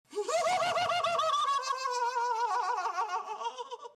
IT Chapter 2 Pennywise Laugh Sound Effect Free Download
IT Chapter 2 Pennywise Laugh